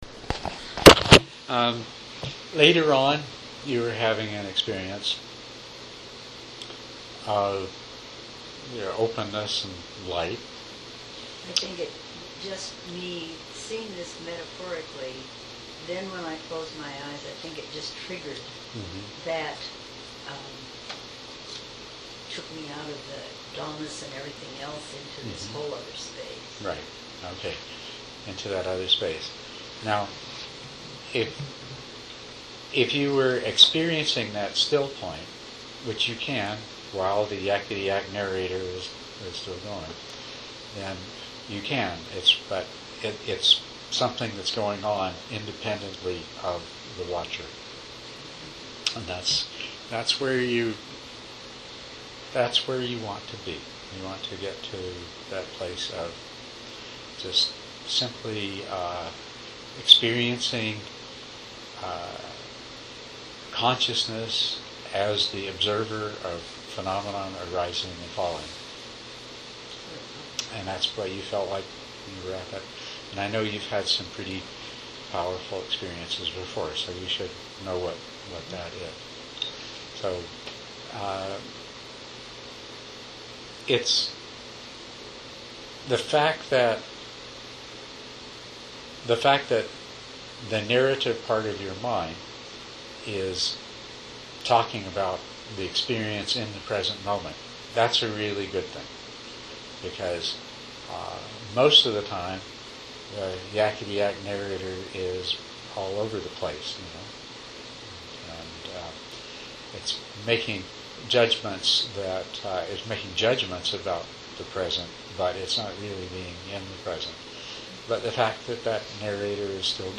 Monday, Dharma Talk #3 Play the recording below or right click here to save to your computer.